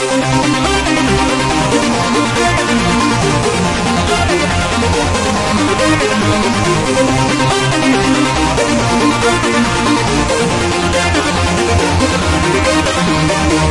恍惚循环。
TECHNO 法兰 俱乐部 低音 合成器 节拍 神志恍惚 140-BPM 电子 旋律 狂欢 扭曲 变形 房子 舞蹈 序列